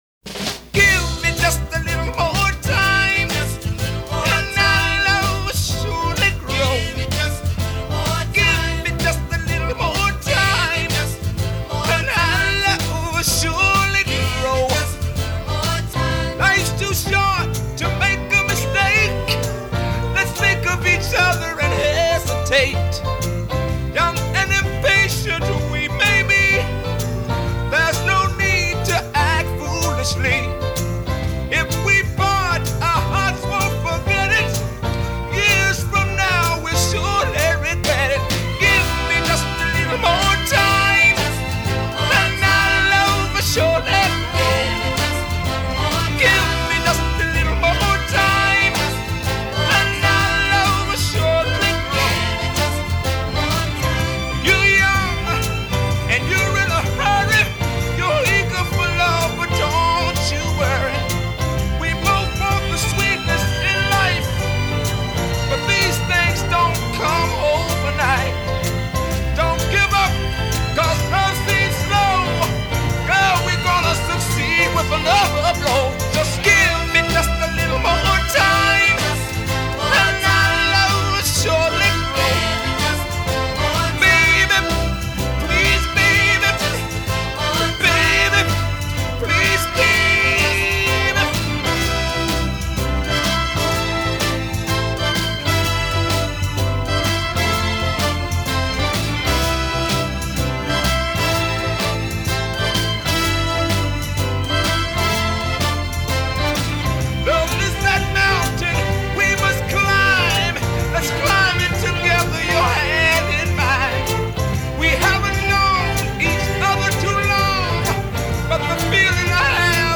a four-man vocal group from Detroit.